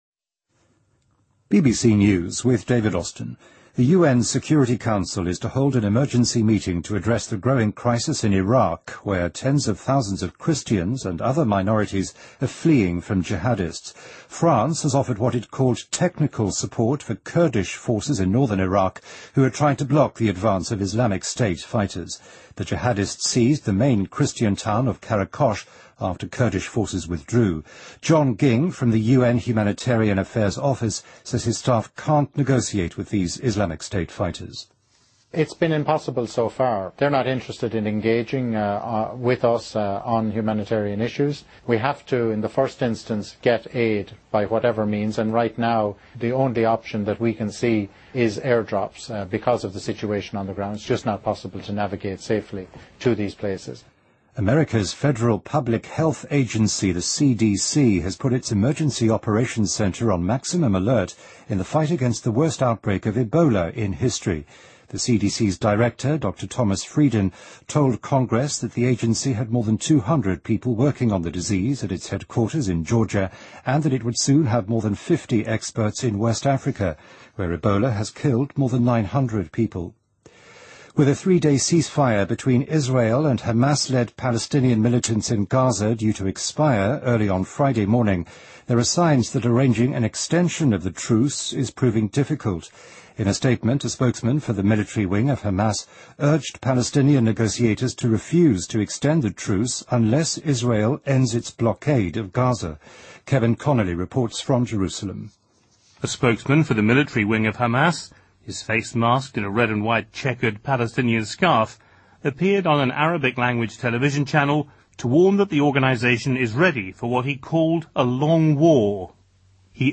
BBC news,阿根廷政府要求海牙国际法庭对美国提起诉讼